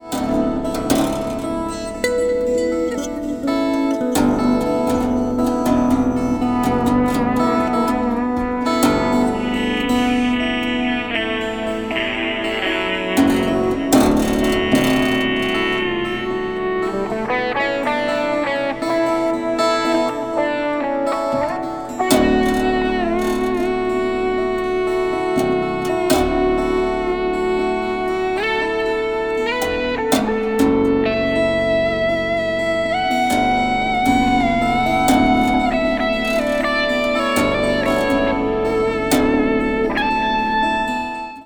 Electric guitar